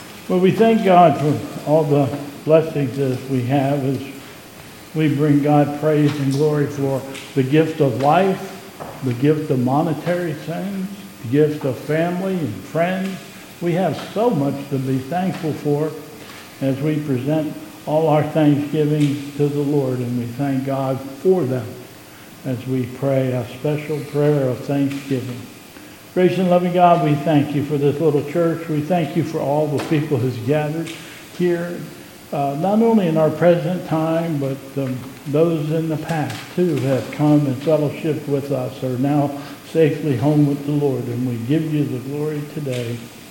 2022 Bethel Covid Time Service
Offertory
Prayer of Thanksgiving and singing of the Doxology